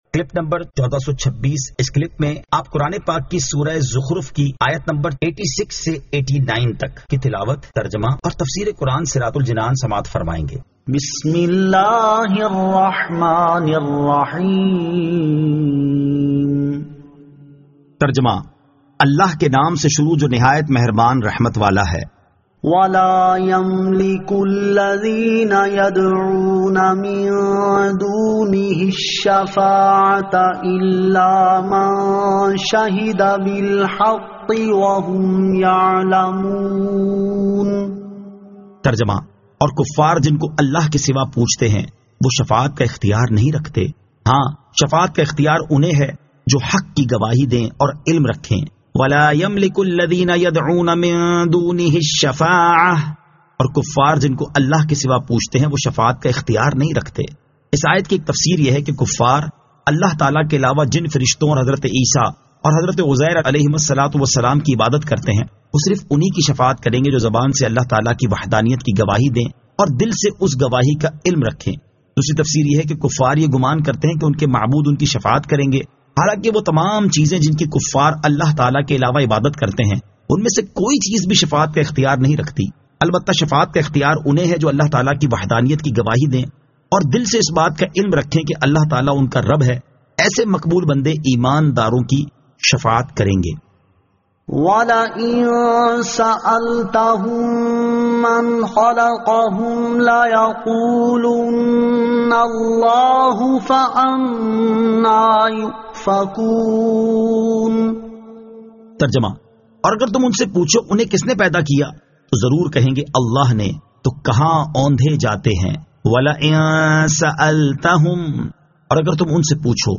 Surah Az-Zukhruf 86 To 89 Tilawat , Tarjama , Tafseer